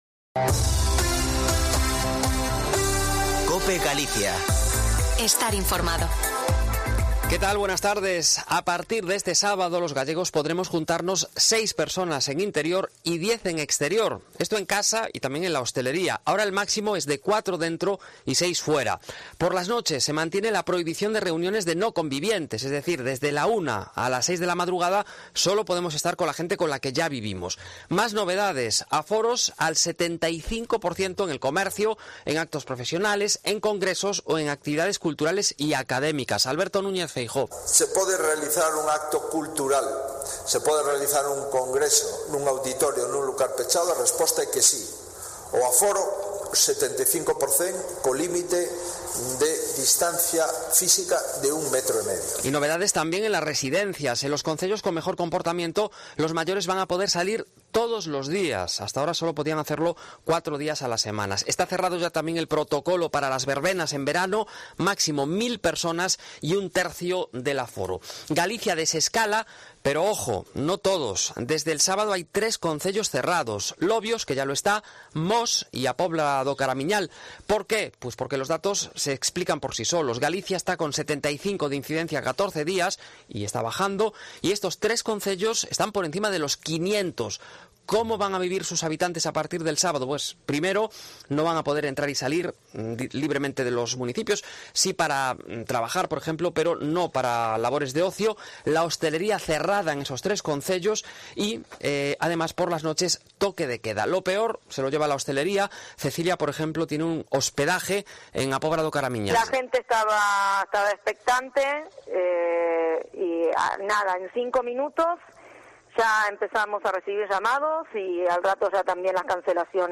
Informativo Mediodía Cope Galicia 26/05/2021.